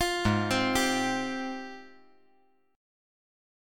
AbM13 Chord
Listen to AbM13 strummed